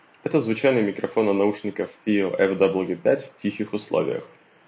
Микрофон FiiO FW5 на 5 из 10, он меня расстроил, он на уровне бюджетников за 30-40$, особенно в шумных условиях.
В тихих условиях: